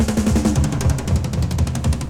02_22_drumbreak.wav